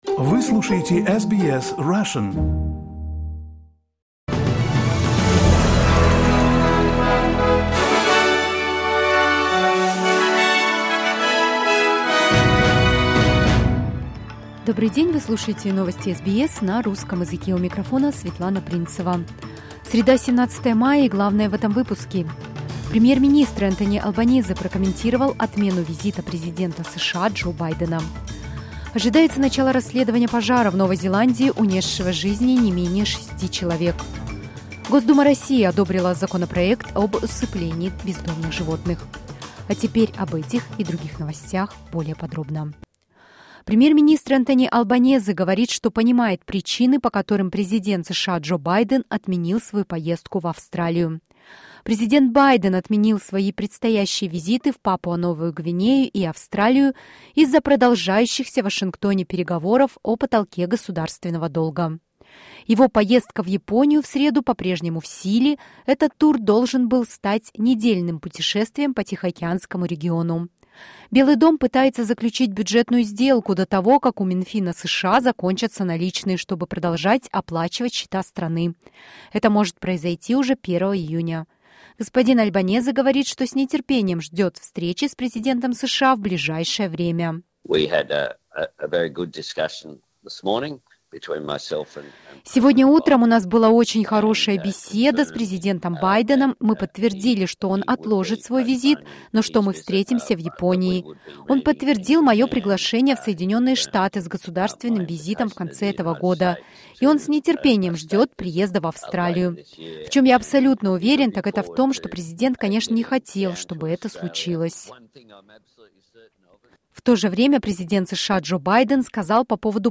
SBS news in Russian — 17.05.2023